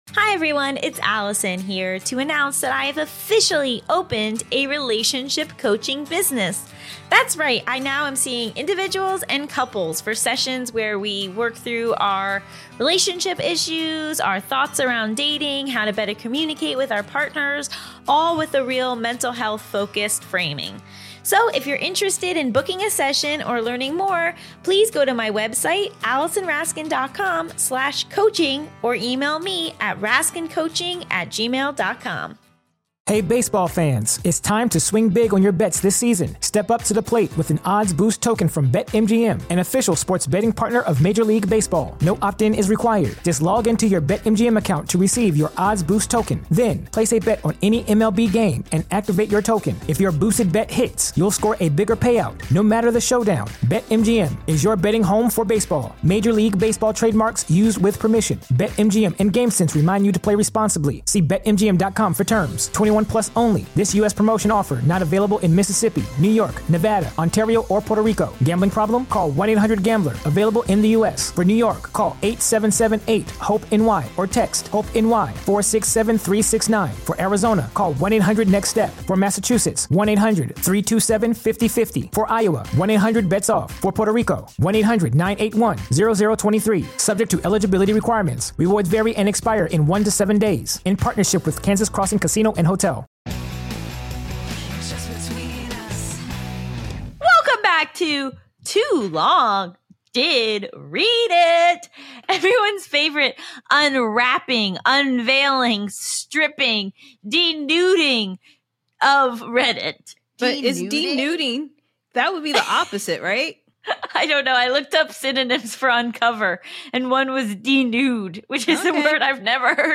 r/texts gets a dramatic reading that is UNFORGETTABLE. A man leaves a woman a devastating review of her kissing style.